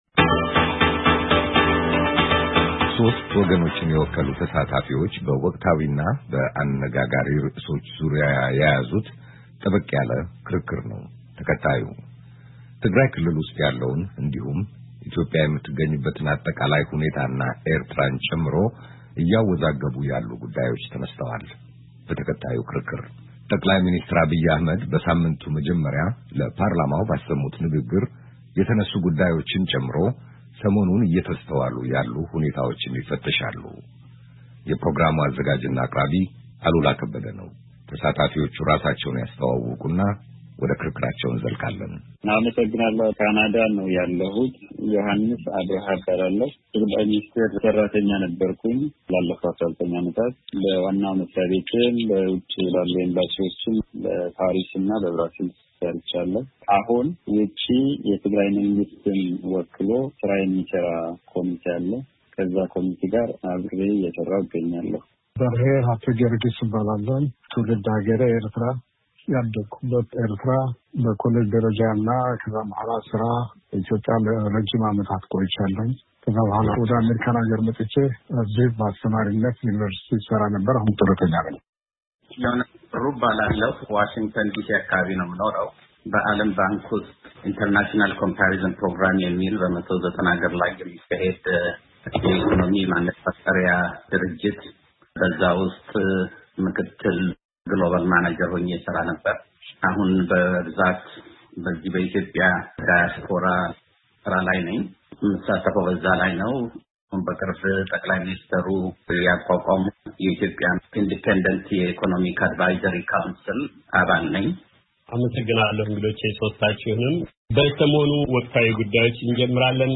ክርክር፦ የትግራዩ ቀውስ ፈተና - የሰላም እና አብሮ የመኖር እጣ